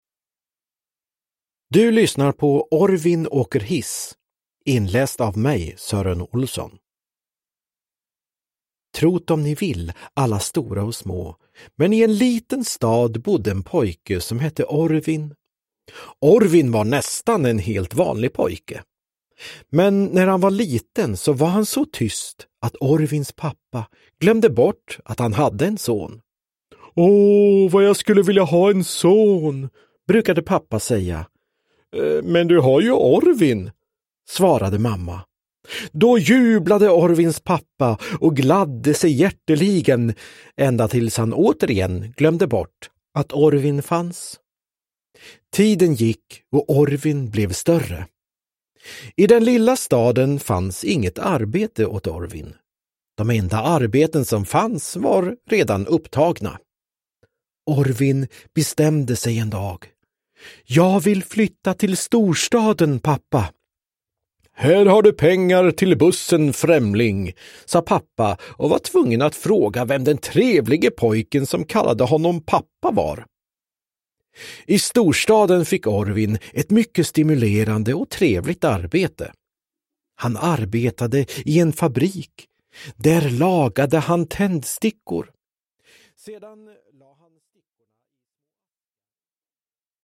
Uppläsare: Sören Olsson, Anders Jacobsson